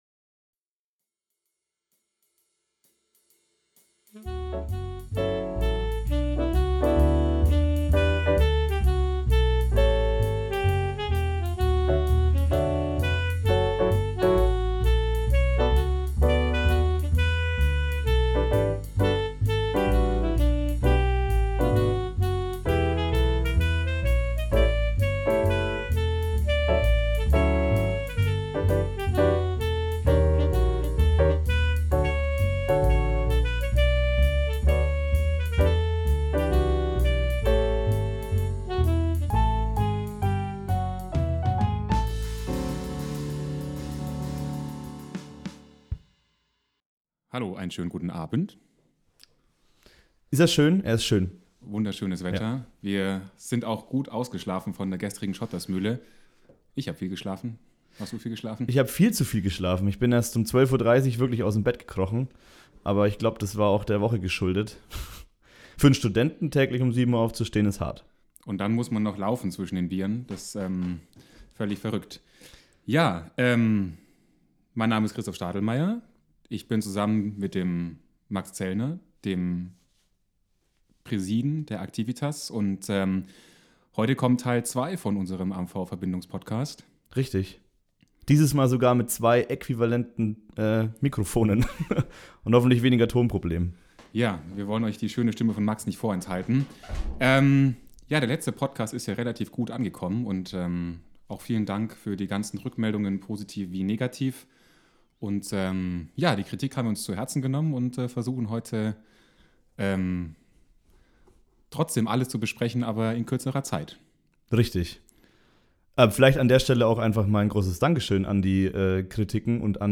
Der AHV-Vorsitzende und der Aktivenpräside blicken Anfang Juli 2023 gemeinsam auf die letzten Monate zurück und stellen die nächsten Veranstaltungen des Sommersemesters vor.